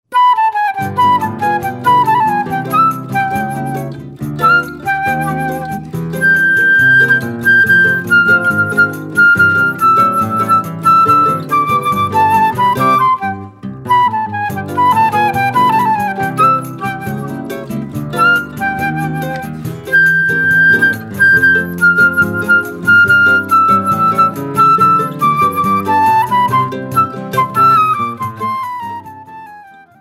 flauta